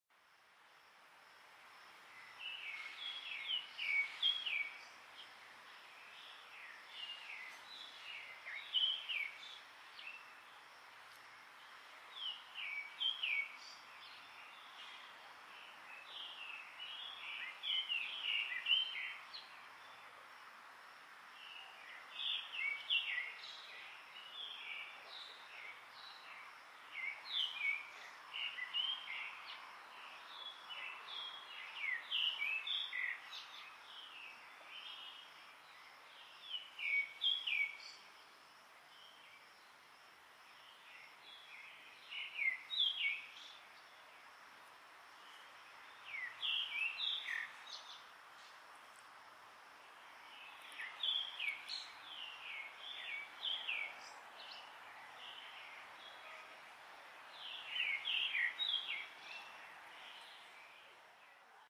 birdsounds